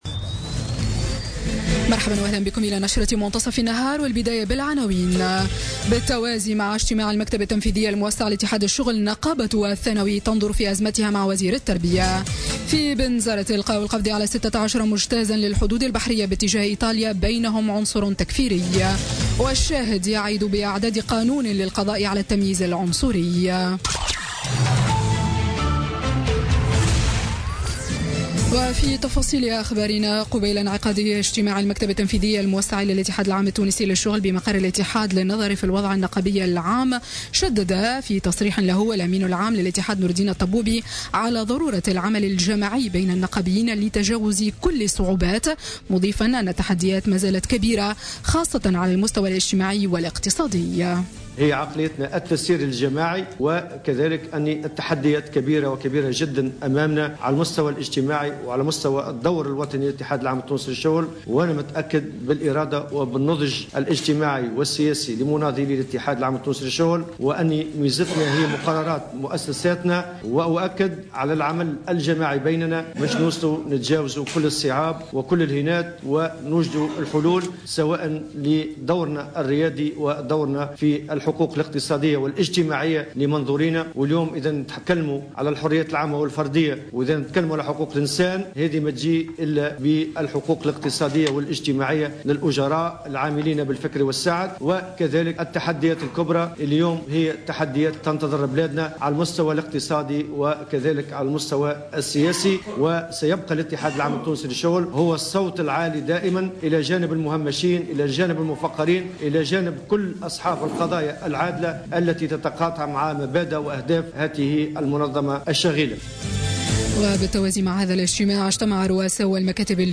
نشرة أخبار منتصف النهار ليوم الثلاثاء 21 مارس 2017